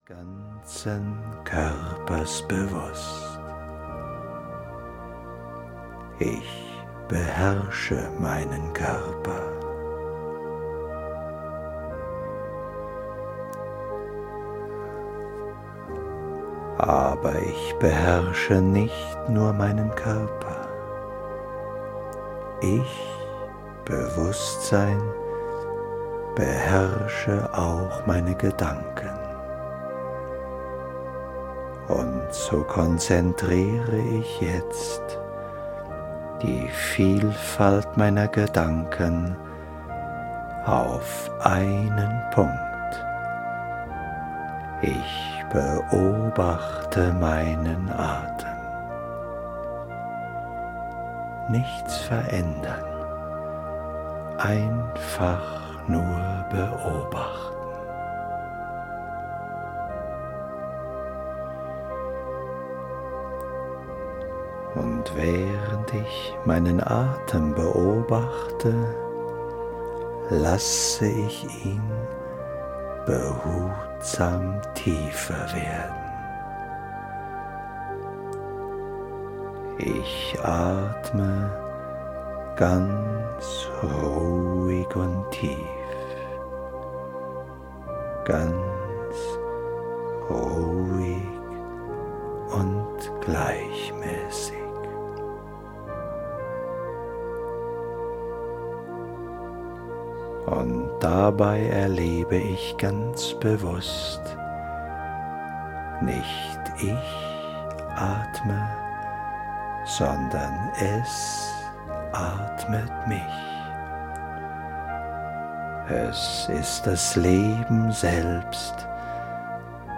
Tepperwein Taothek : Heil sein! Heile dich selbst (Day & Night) - Hörbuch